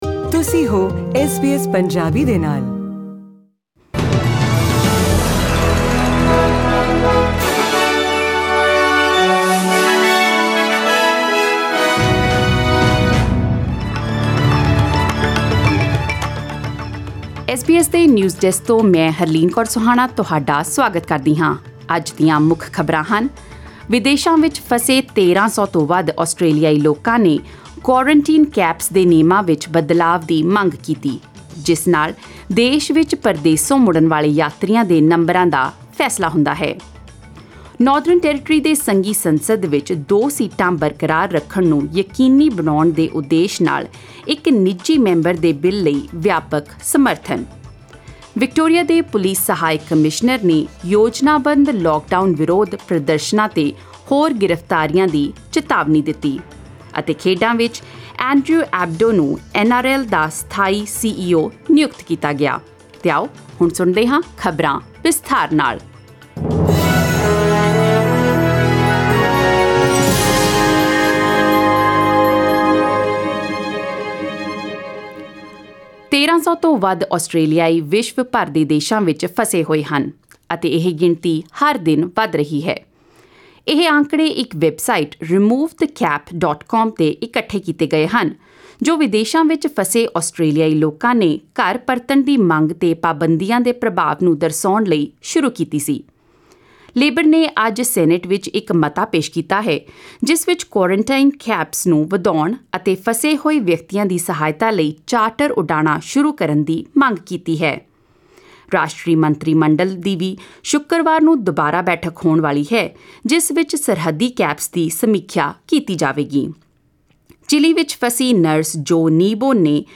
Australian News in Punjabi : 3 September 2020